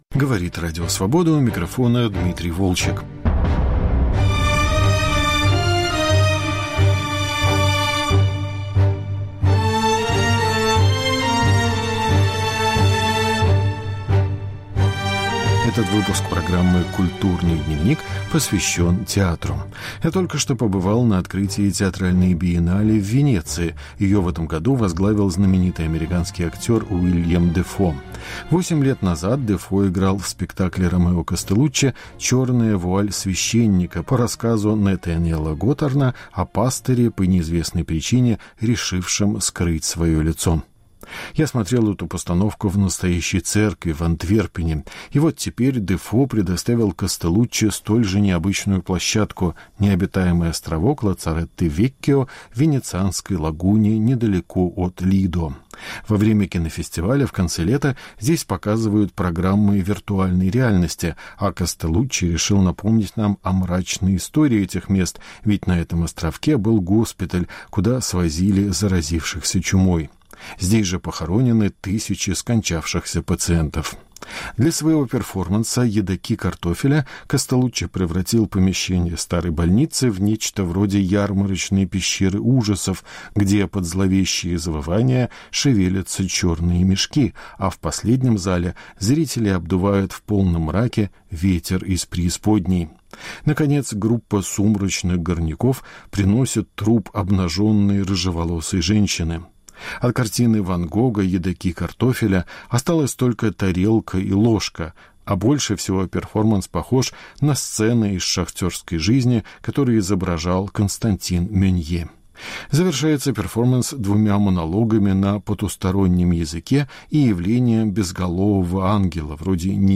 Разговор с московским режиссером, поставившим спектакль на Бродвее